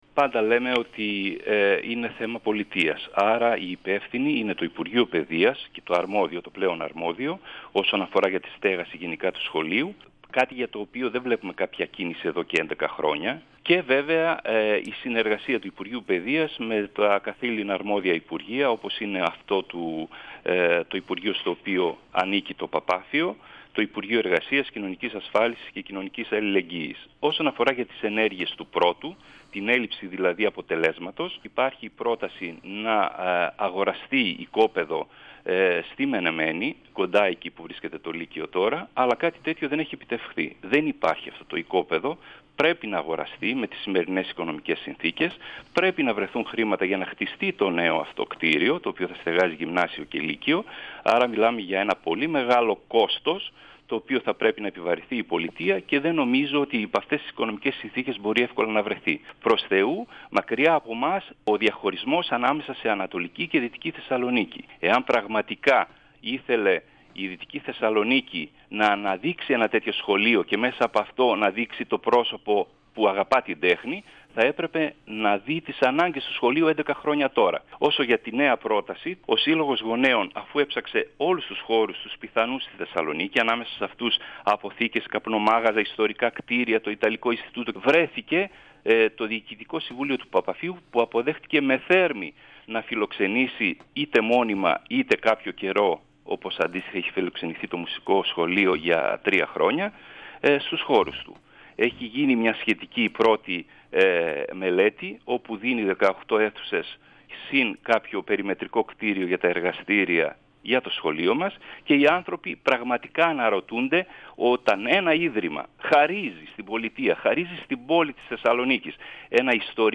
Συνέντευξη στην εκπομπή «Μάθε τι παίζει»